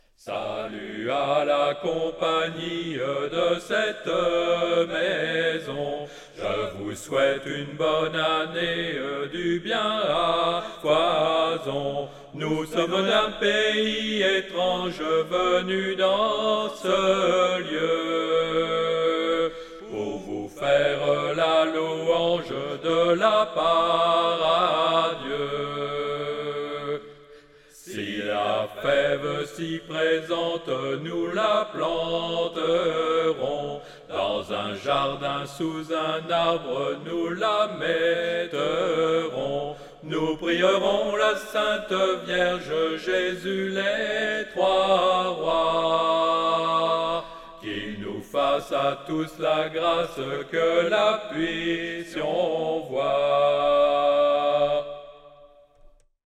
traditionnel
Alto
à 4 voix